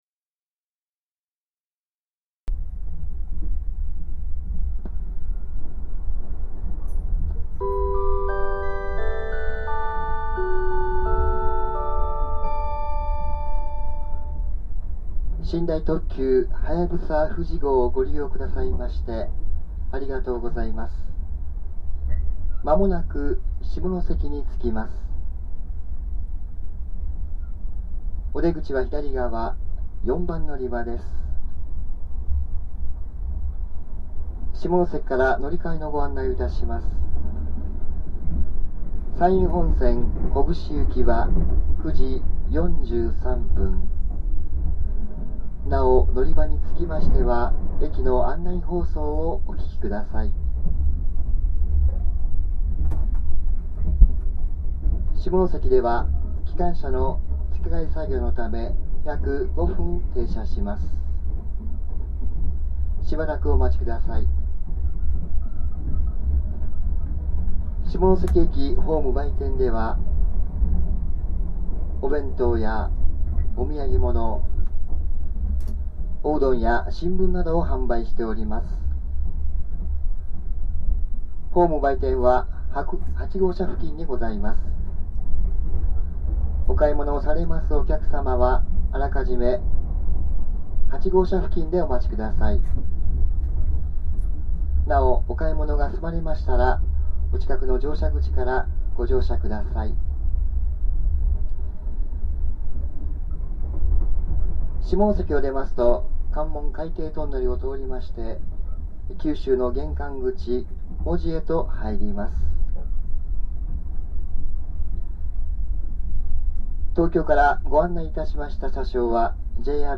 下関駅到着前の車内放送「ありがとう　そして　さようなら」（2009年3月7日　録音　3分16秒）